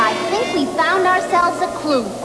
Here you will find tons of great sounds and other downloads from actual episodes of Scooby Doo. Choose from any of over 150 wav files, each of excellent sound quality.